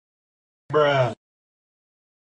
Bruh Meme Sound Effect sound effects free download